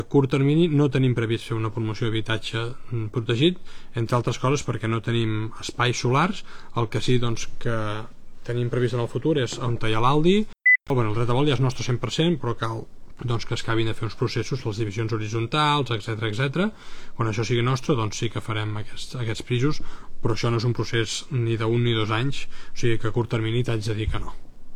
En l’últim directe a Instagram, l’alcalde de Calella, Marc Buch, ha explicat que, tot i que la ciutat compta amb 180 habitatges socials gestionats per la Fundació Hàbitat Solidari, no es preveu impulsar noves promocions a curt termini.